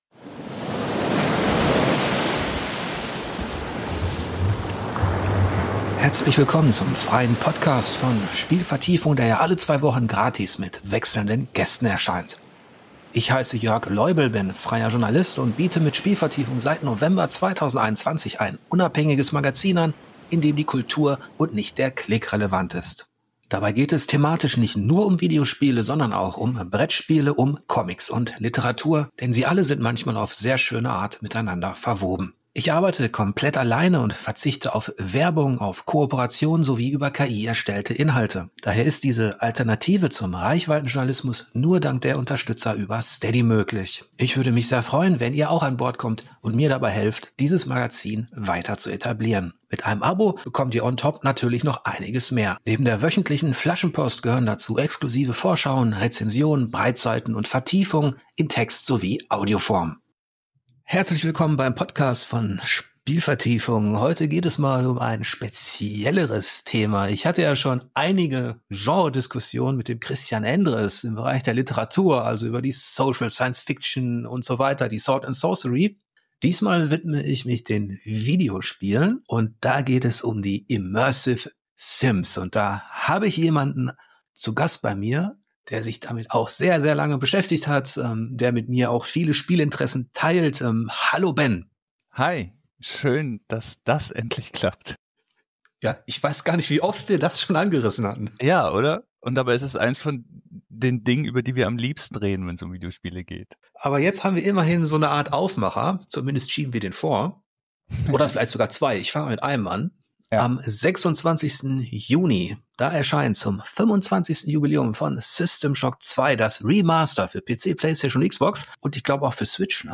Spielanalyse im Gespräch: Assassin's Creed Shadows. – Spielvertiefung: Podcast (frei) – Podcast